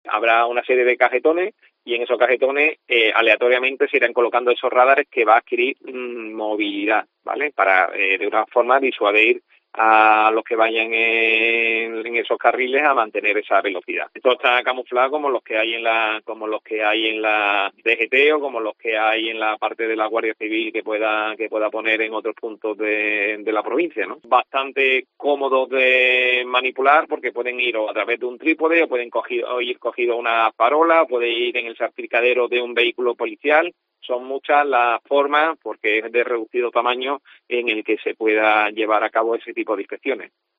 AUDIO: Avelino Barrionuevo concejal de Seguridad del Ayuntamiento de Málaga